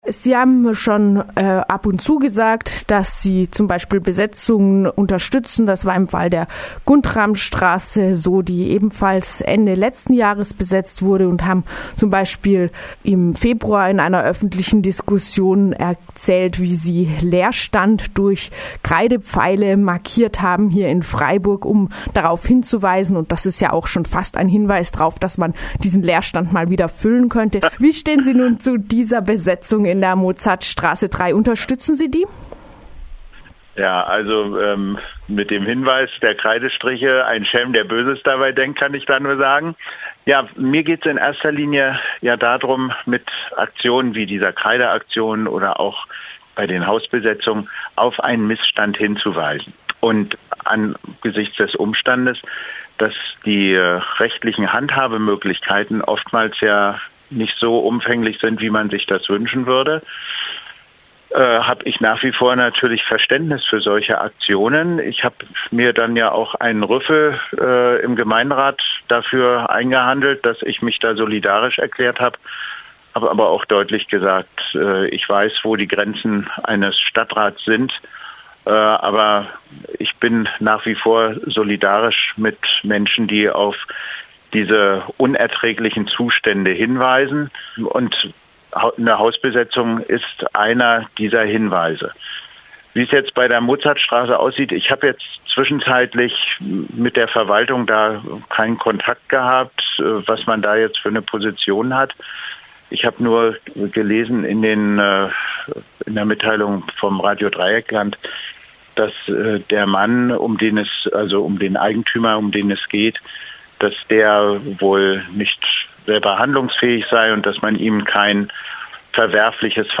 Mehrfach gegen Leerstand und für Hausbesetzungen ausgesprochen hatte sich SPD-Stadtrat Walter Krögner. Wir haben bei ihm daher nachgefragt, ob er sich mit der besetzten Mozart 3 solidarisch erklärt.